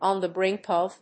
アクセントon [at] the brínk of…